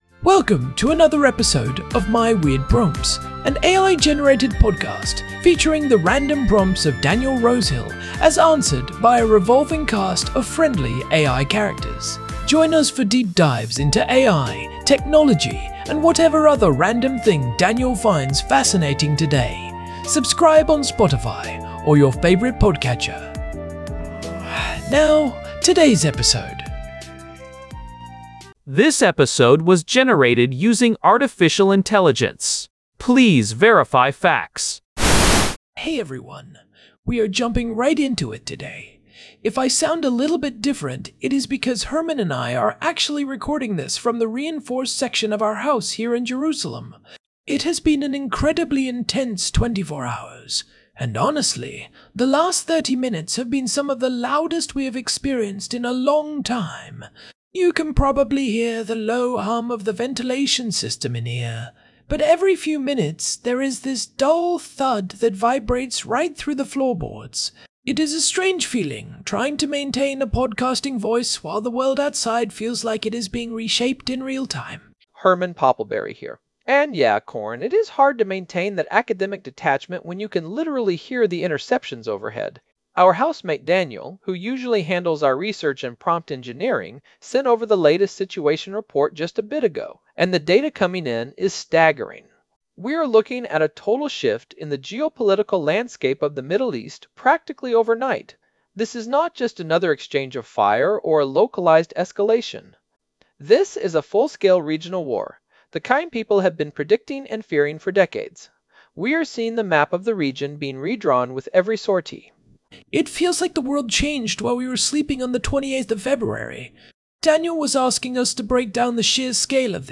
Recording from a reinforced shelter in Jerusalem